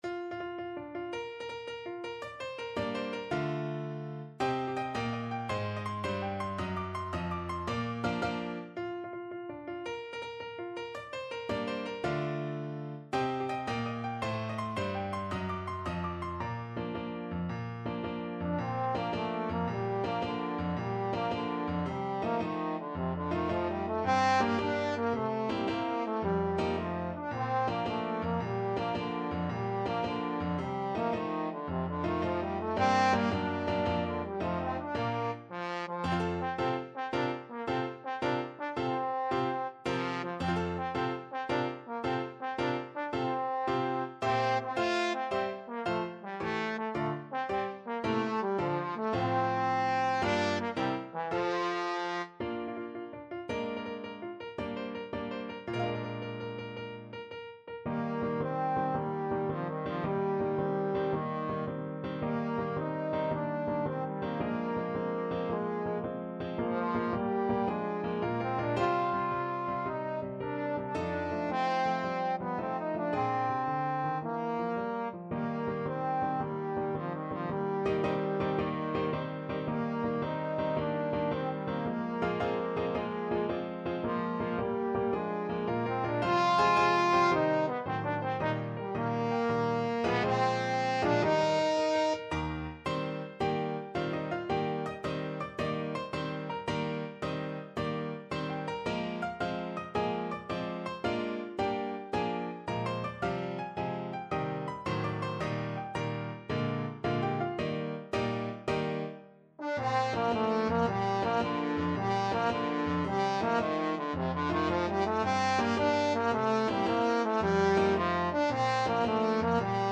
March .=c.110
6/8 (View more 6/8 Music)
C4-F5
Classical (View more Classical Trombone Music)